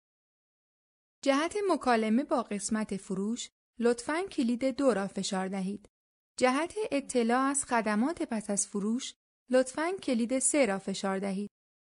• 7Persian Female No.3
Commercial